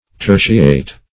Tertiate \Ter"ti*ate\, v. t. [L. tertiatus, p. p. of tertiare to